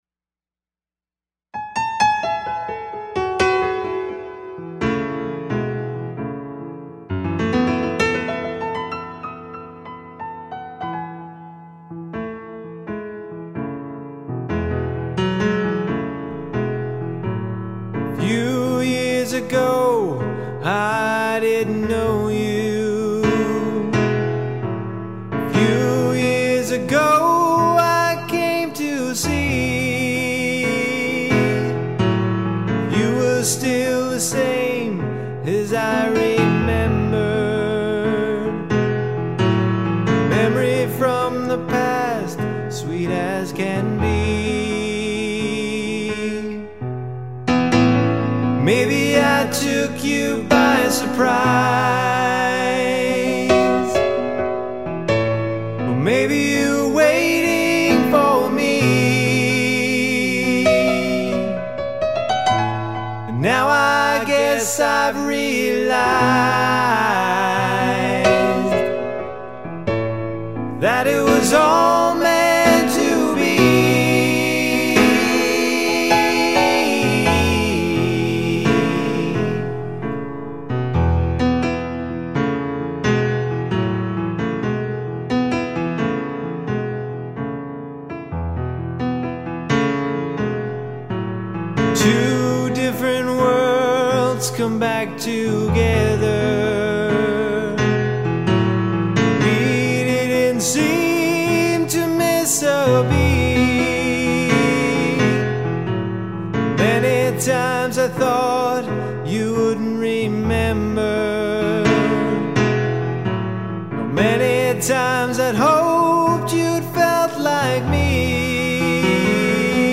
Piano: